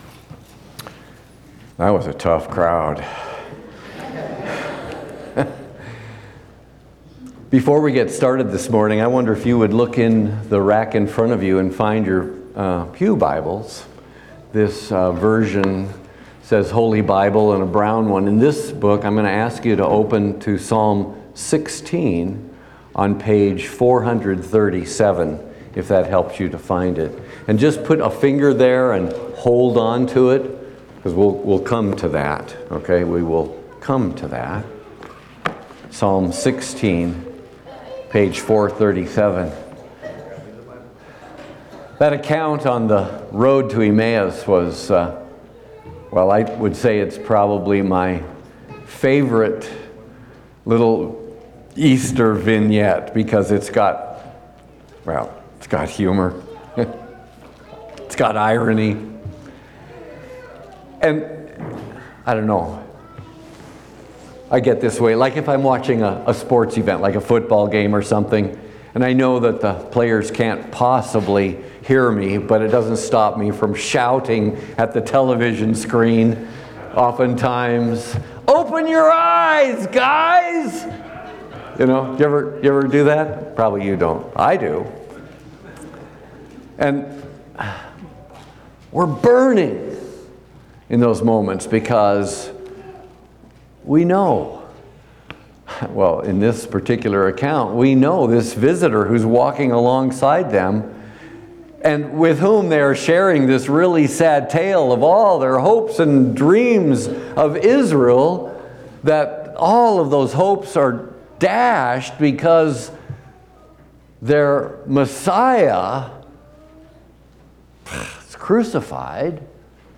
Third Sunday of Easter